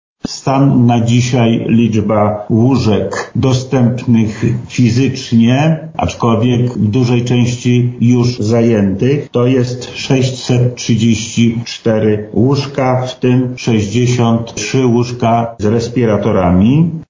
— mówi Wojewoda Lubelski, Lech Sprawka.